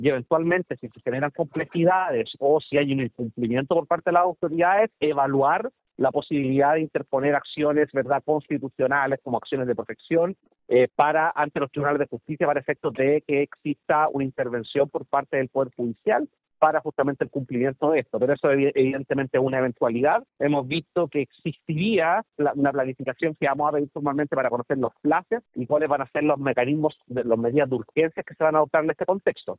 En conversación con La Radio, Quesille indicó que tras su visita, se evaluarán los pasos a seguir por la defensoría, sin descartar eventuales acciones legales, en el caso de que no se cumpla el programa de las instituciones para remediar las falencias.